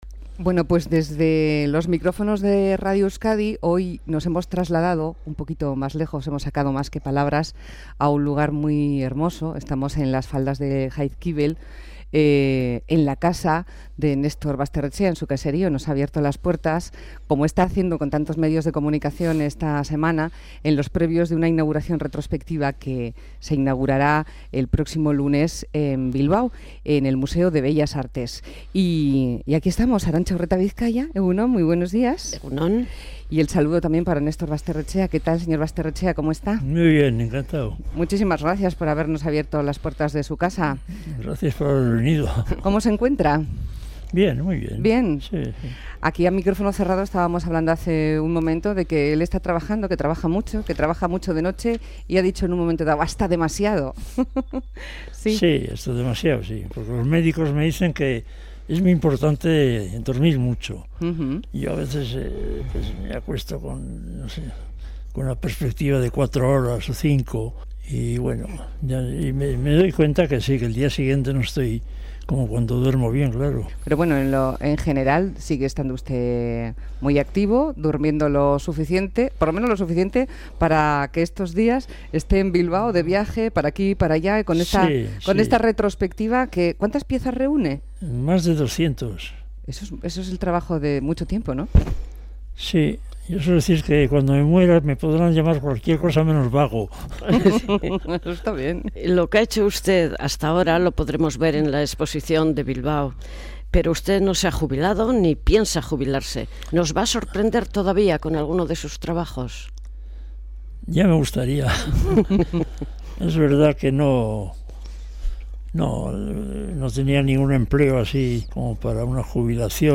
Entrevista a Nestor Basterretxea